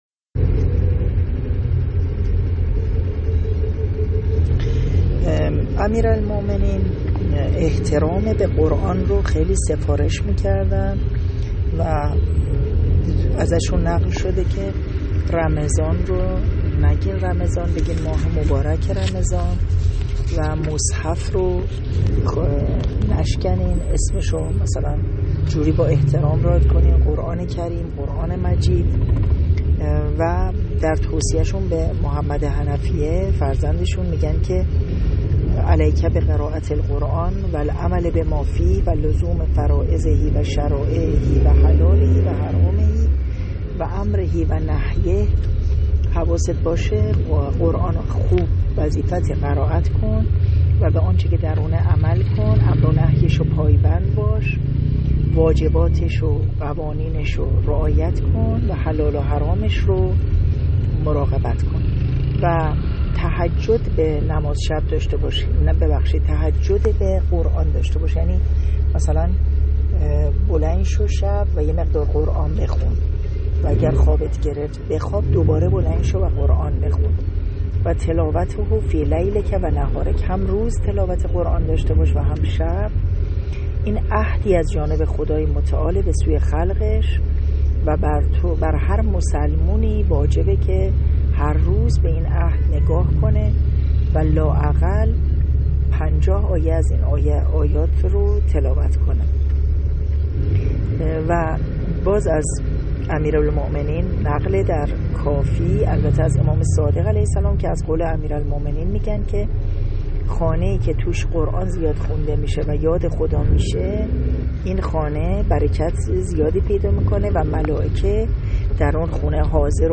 قرآن‌پژوه